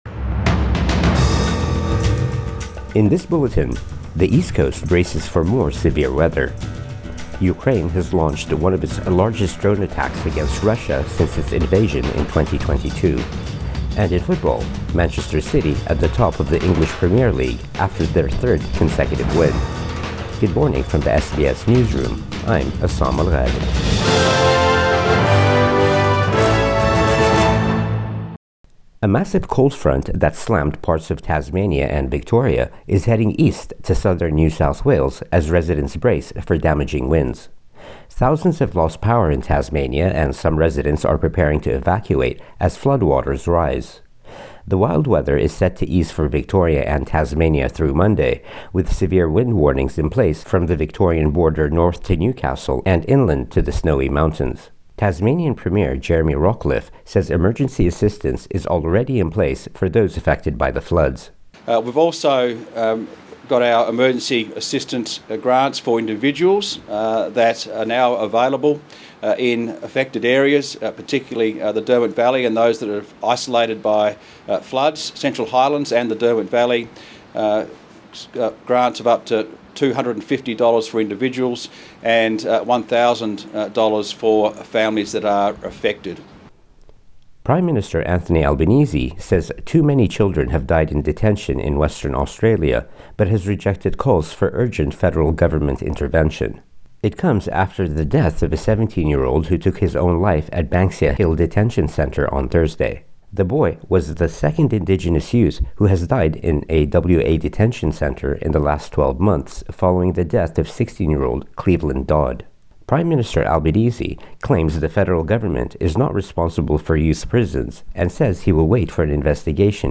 Morning News Bulletin 2 September 2024